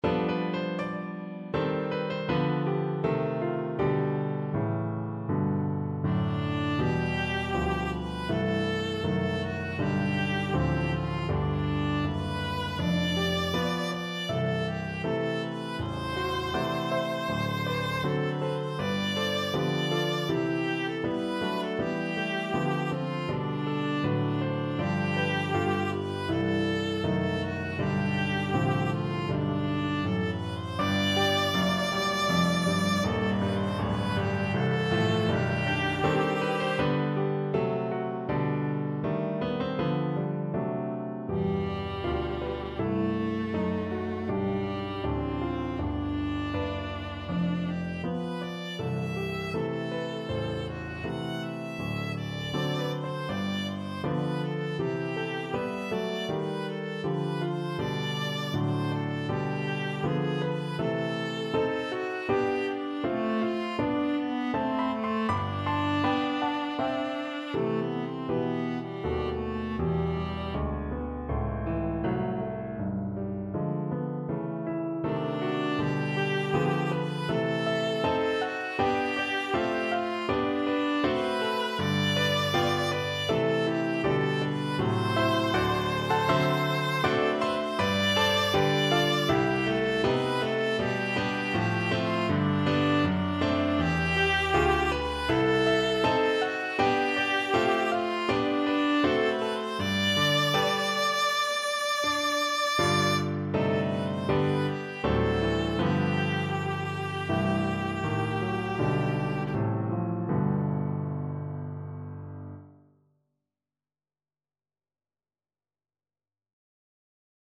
Viola
G major (Sounding Pitch) (View more G major Music for Viola )
4/4 (View more 4/4 Music)
Andante
G4-F#6
Traditional (View more Traditional Viola Music)